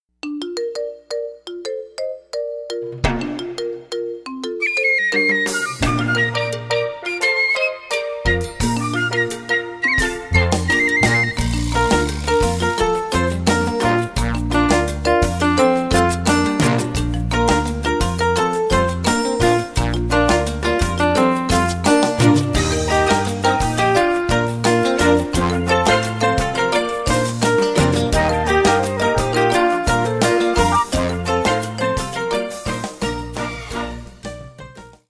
play along with a full symphony orchestra